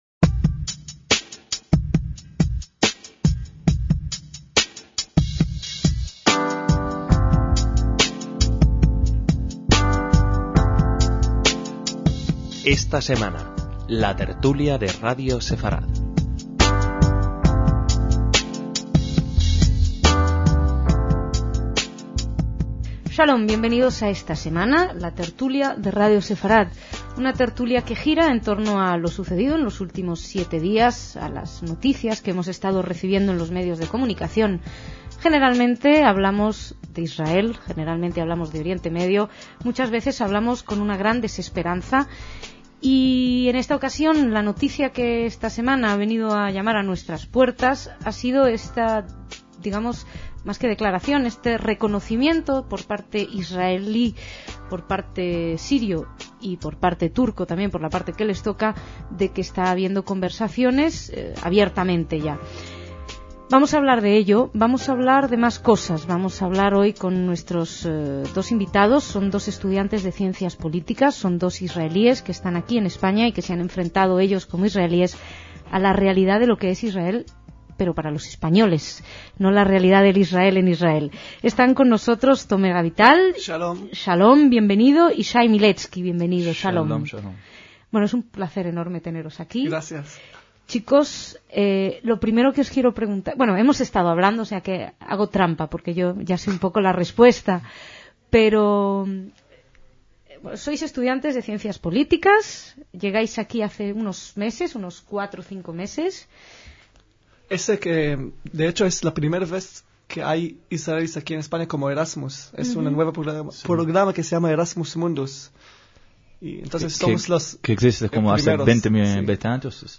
DECÍAMOS AYER (24/5/2008) - En esta ocasión, los invitados al debate fueron dos estudiantes israelíes de Ciencias Políticas de Erasmus en España.